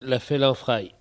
Saint-Jean-de-Monts
Catégorie Locution